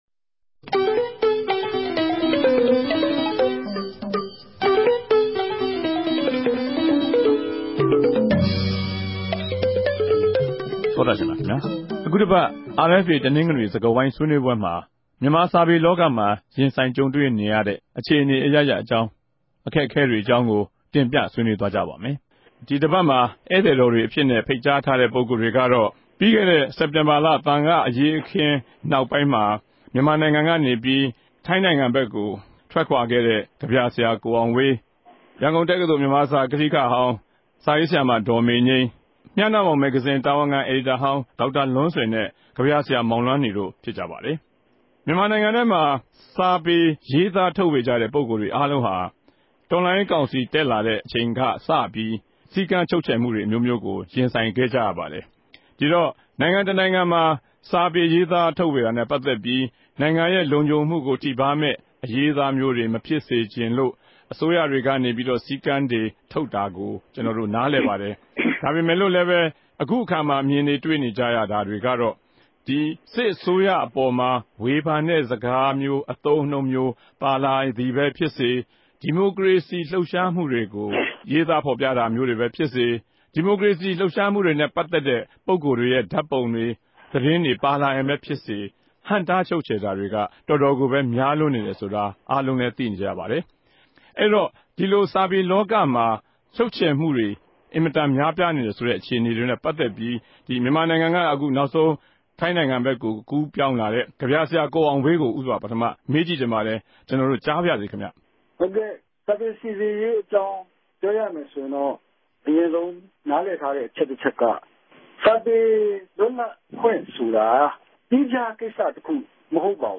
စကားဝိုင်း